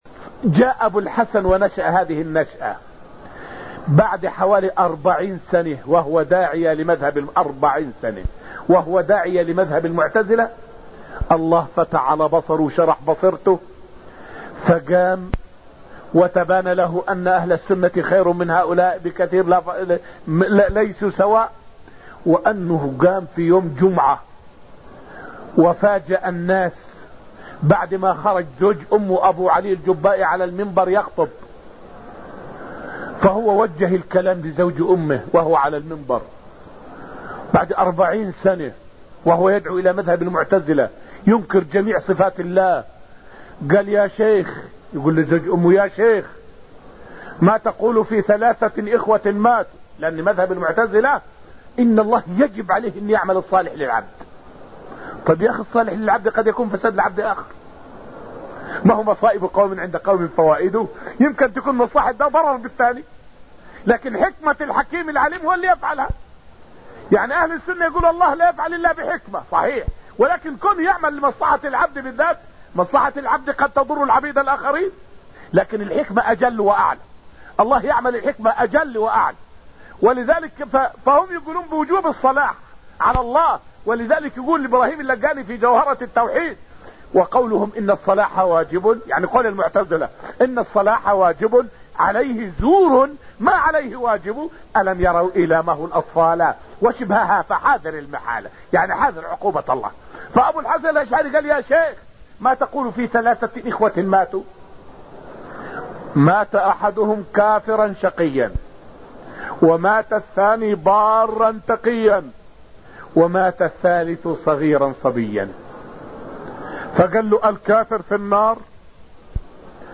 فائدة من الدرس الأول من دروس تفسير سورة النجم والتي ألقيت في المسجد النبوي الشريف حول مناظرة أبو الحسن الأشعري وأبو علي الجبّائي.